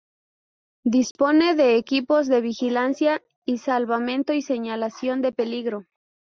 Pronounced as (IPA)
/seɲaliθaˈθjon/